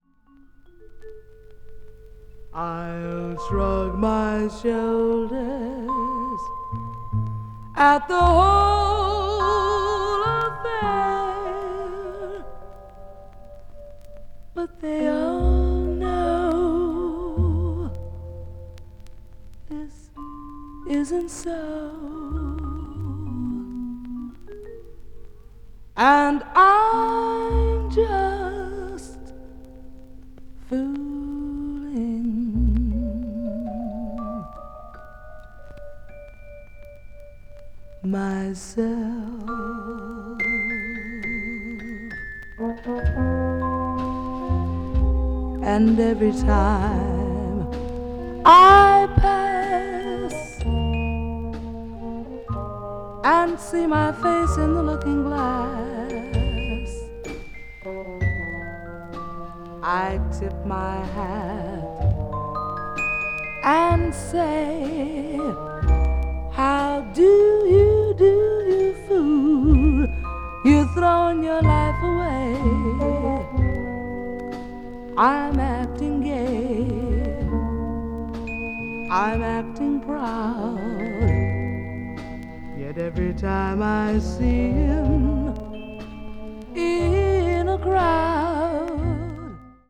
a Late Session at an Intimate Club
media : EX-/EX-(薄いスリキズによるわずかなチリノイズ/一部軽いチリノイズが入る箇所あり)
blues jazz   jazz ballad   jazz vocal